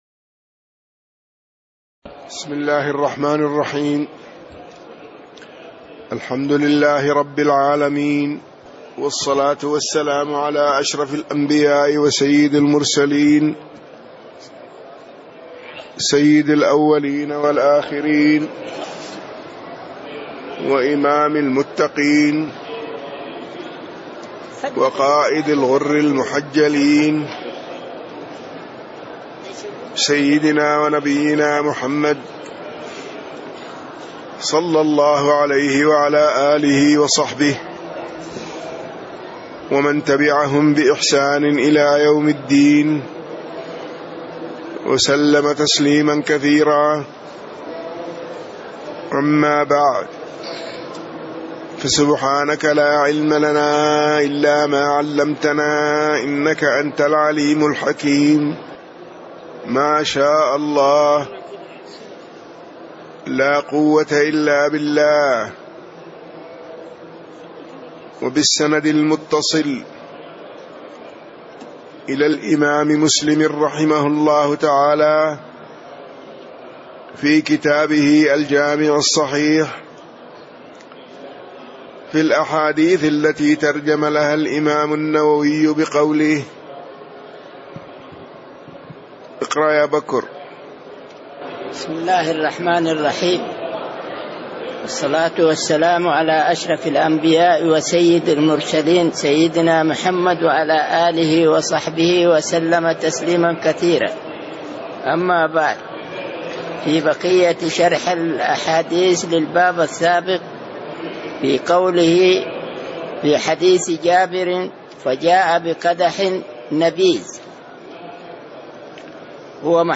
تاريخ النشر ١٦ رجب ١٤٣٦ هـ المكان: المسجد النبوي الشيخ